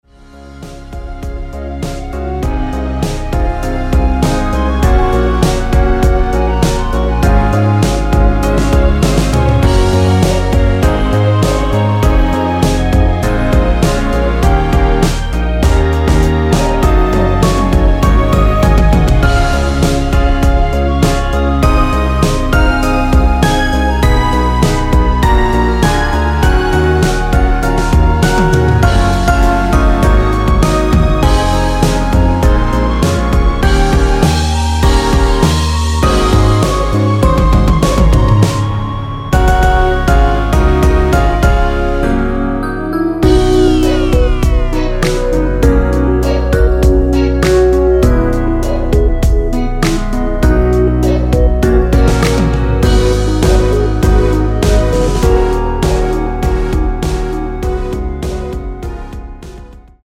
원키 멜로디 포함된 MR입니다.(미리듣기 확인)
멜로디 MR이라고 합니다.
앞부분30초, 뒷부분30초씩 편집해서 올려 드리고 있습니다.
중간에 음이 끈어지고 다시 나오는 이유는